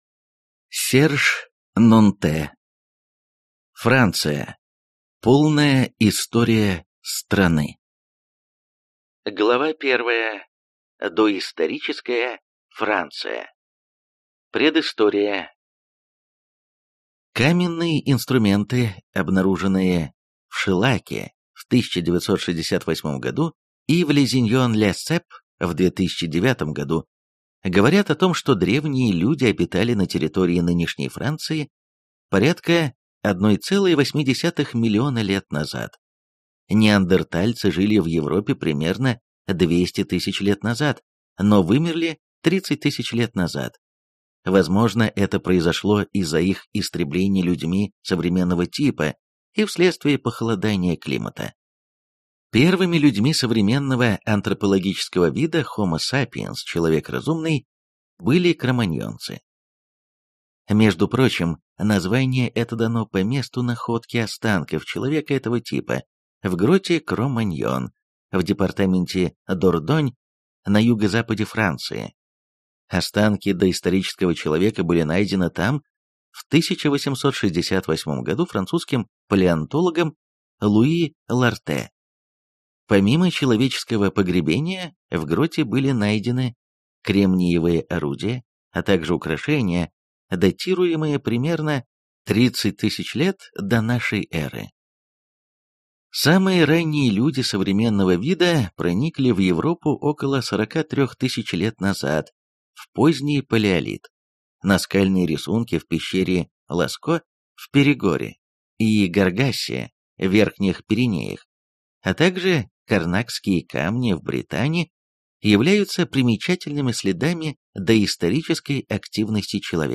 Аудиокнига Франция. Полная история страны | Библиотека аудиокниг